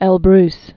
(ĕl-brs), Mount